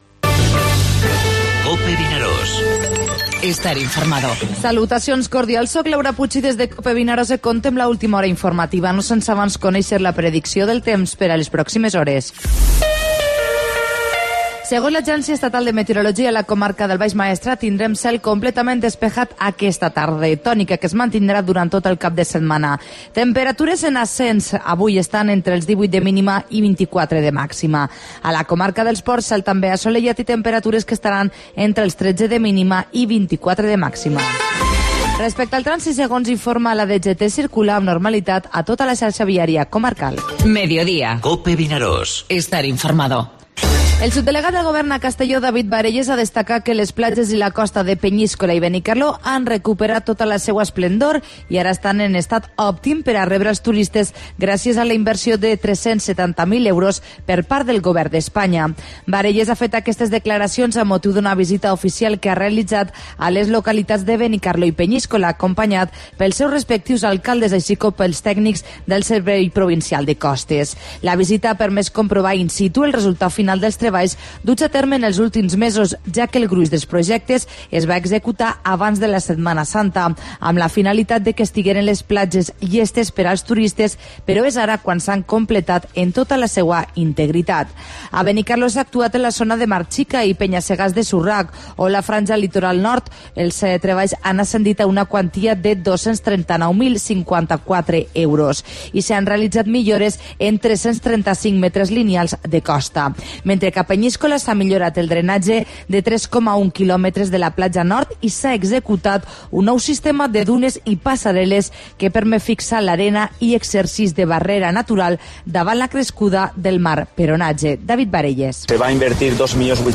Informativo Mediodía COPE al Maestrat (9/6/17)